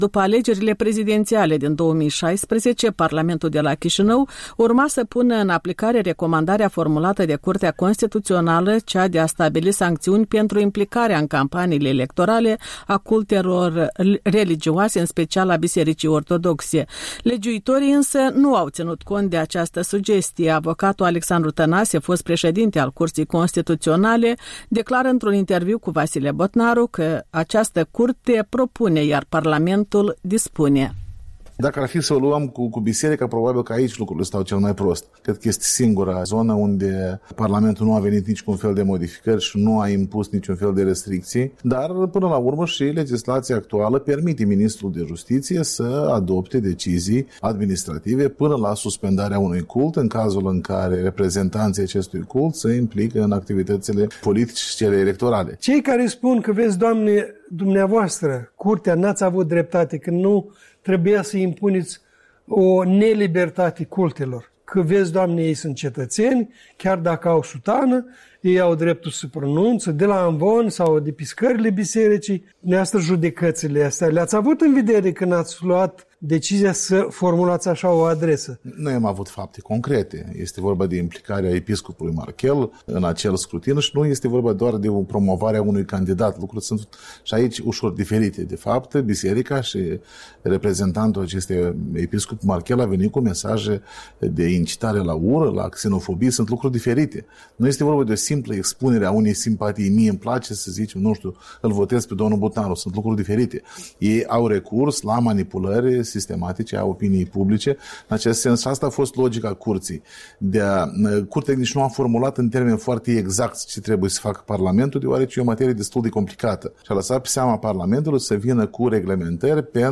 O discuție despre poziția Bisericii Ortodoxe față de politică și alegeri, cu un fost membru al Curții Constituționale.